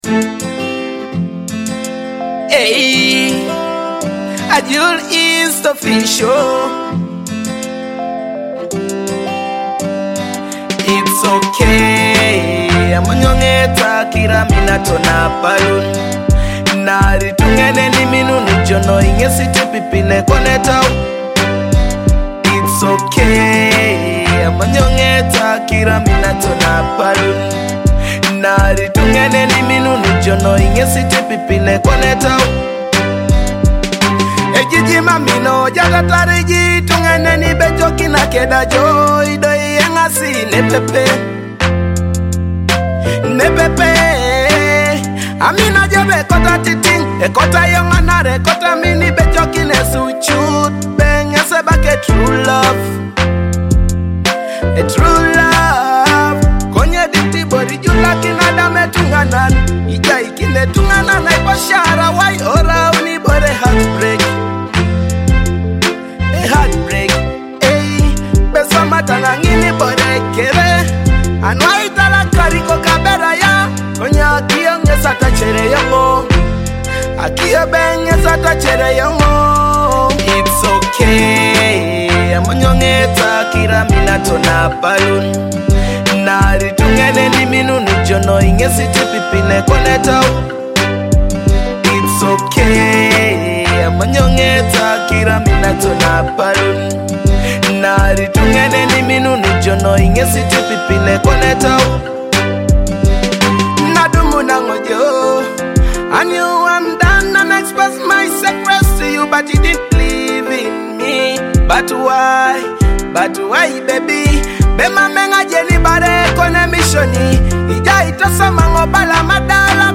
a motivational Teso Afrobeat hit